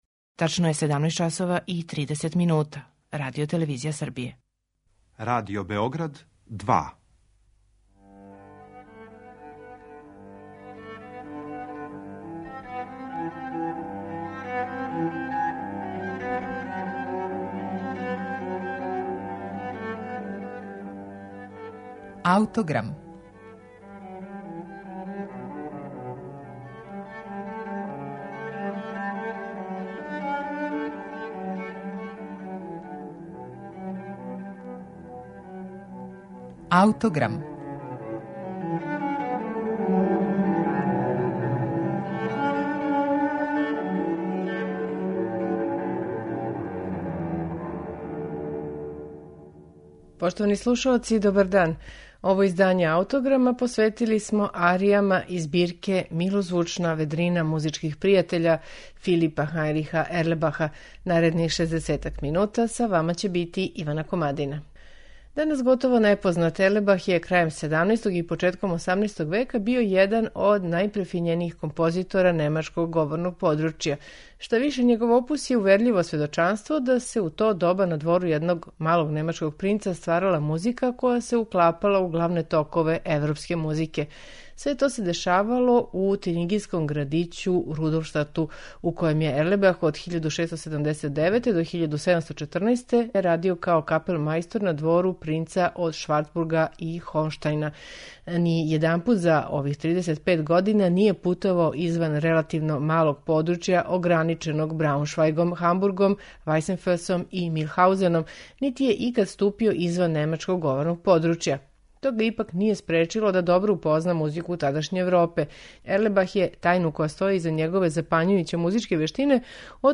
баритон и ансамбл Stylus fantasticus